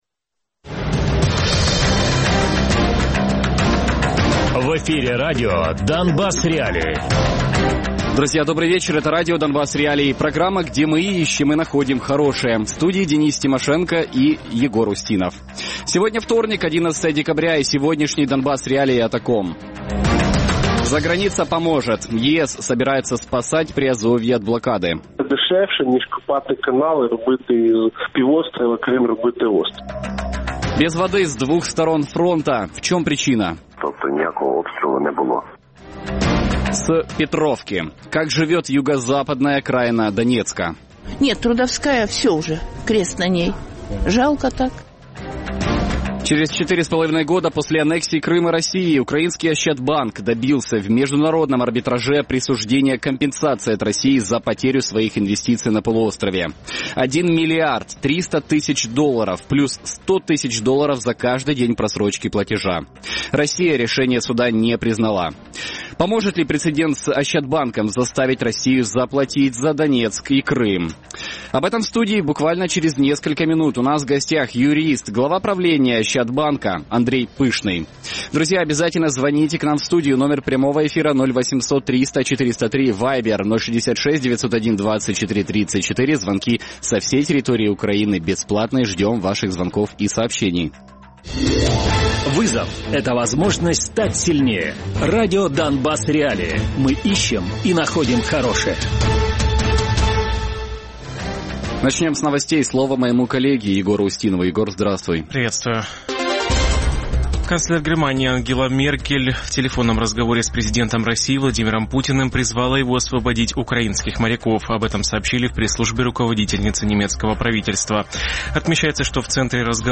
Гості: Андрій Пишний - юрист, голова правління «Ощадбанку» Радіопрограма «Донбас.Реалії» - у будні з 17:00 до 18:00.